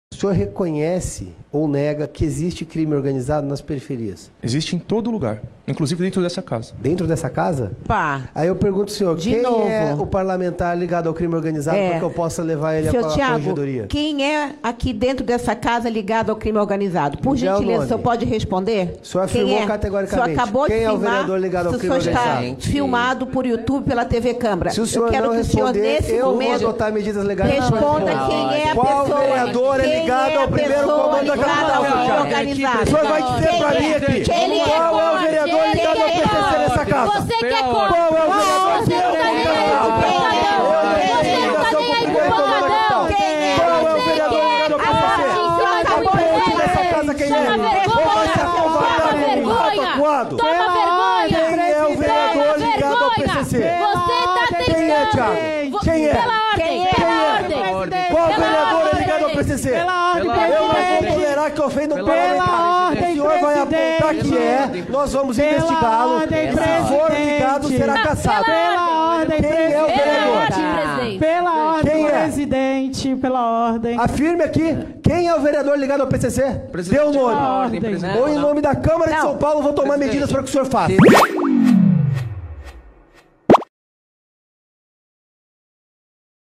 A sessão da CPI dos Pancadões, na Câmara Municipal de São Paulo, terminou em clima de tensão nesta sexta-feira (29).